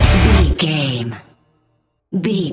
Aeolian/Minor
drums
electric guitar
bass guitar
violin
Pop Country
country rock
bluegrass
happy
uplifting
driving
high energy